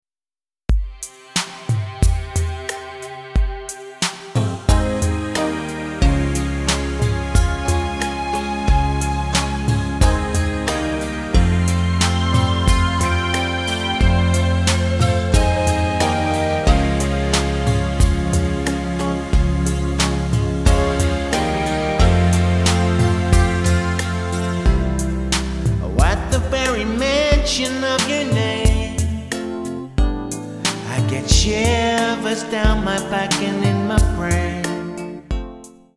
Tonart:F Multifile (kein Sofortdownload.
Die besten Playbacks Instrumentals und Karaoke Versionen .